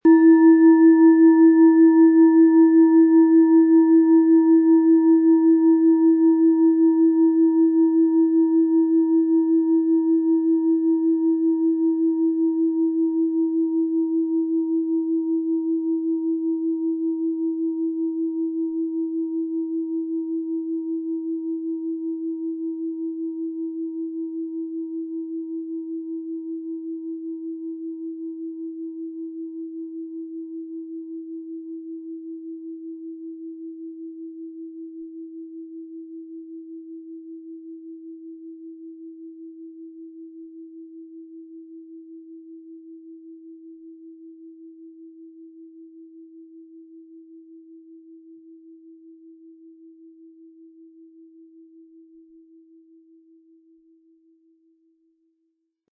Hopi Herzton
Im Sound-Player - Jetzt reinhören können Sie den Original-Ton genau dieser Schale anhören.
Mit dem beiliegenden Klöppel wird Ihre Klangschale mit schönen Tönen klingen.